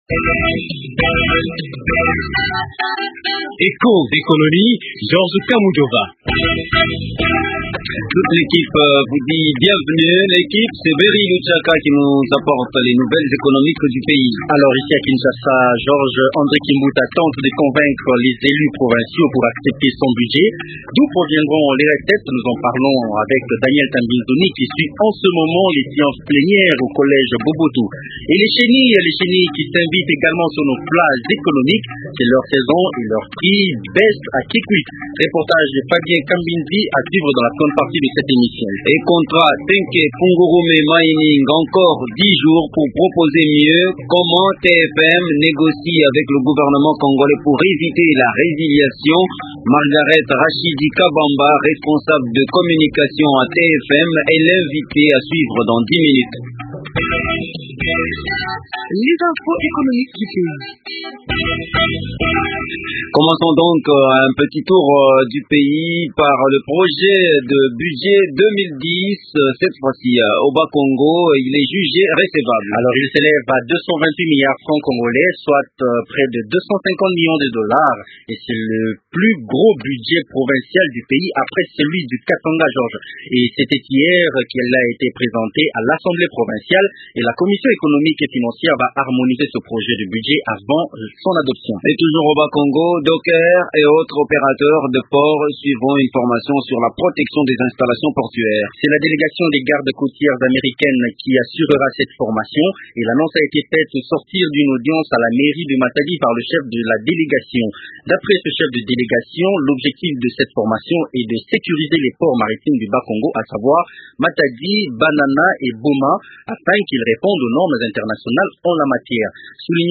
Et le reportage Eco nous conduit à Kikwit où les chenilles s’invitent également sur nos plats économiques.